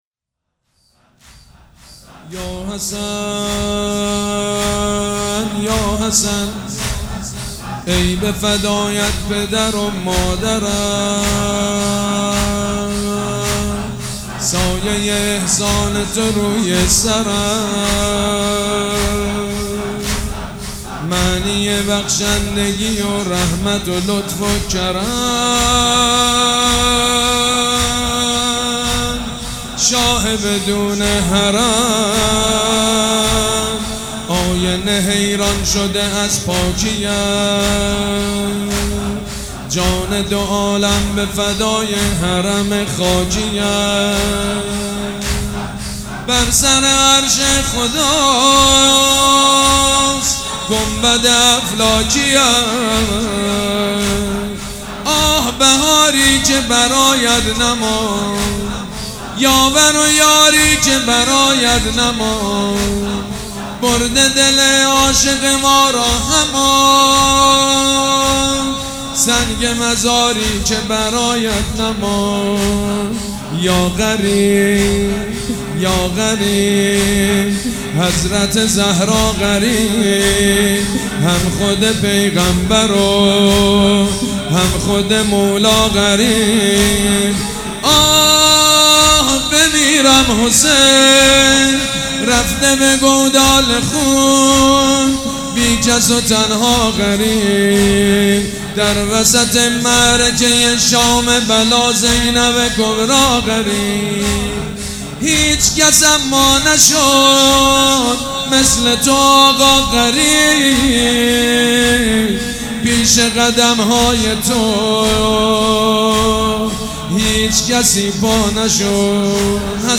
حاج سید مجید بنی فاطمه
مراسم عزاداری شب پنجم